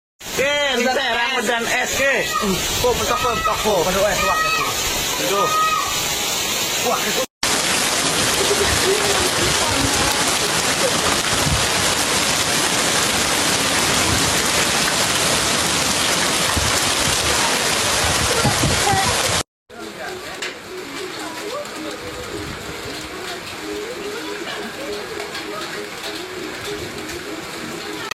Di tempat kalian hujan es sound effects free download
Netizen di Taktakan Kota Serang ini bagikan momen saat terjadinya hujan disertai es di rumahnya, Senin (25/08/2025).